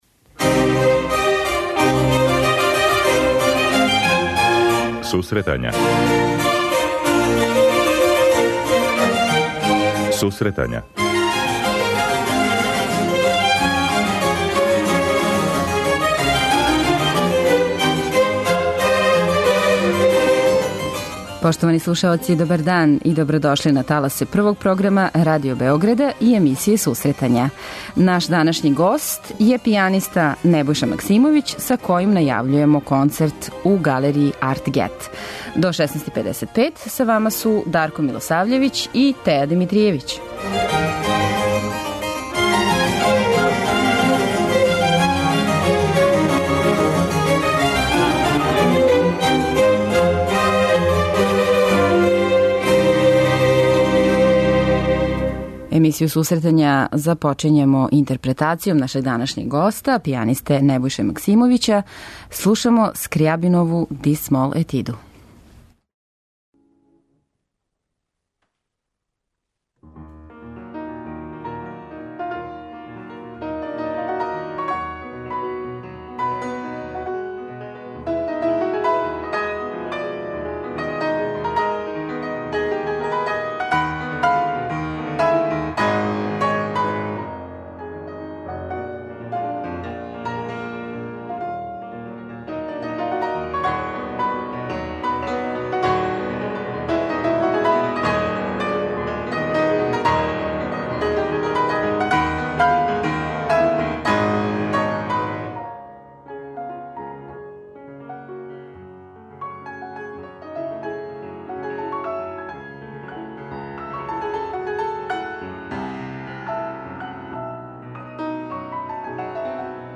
Најављујемо концерт у Галерији Артгет и преслушавамо снимке концертних извођења овог уметника.